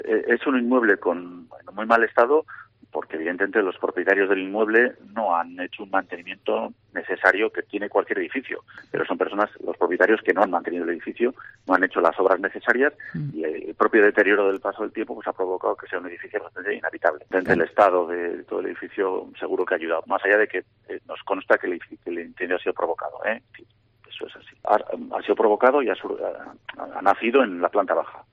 Iñaki Gurtubay, edil de Seguridad de Vitoria explica en COPE que el incendio ha sido provocado